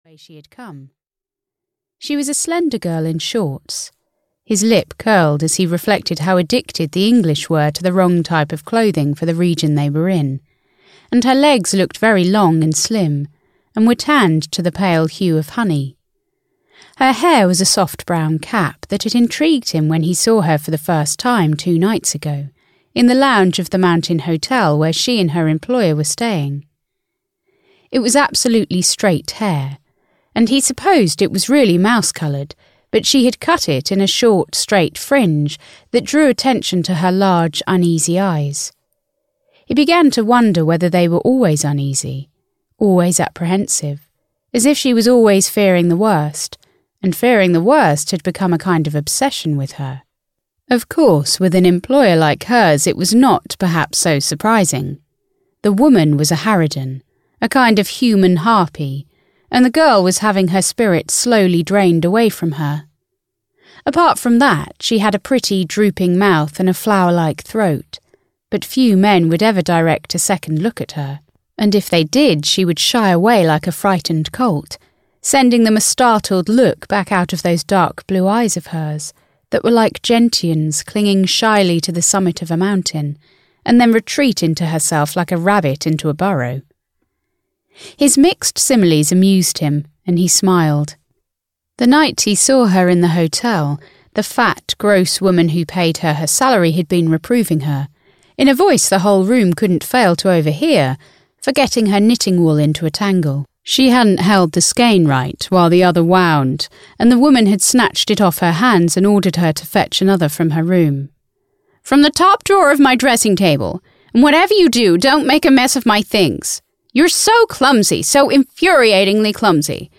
Mountain Magic (EN) audiokniha
Ukázka z knihy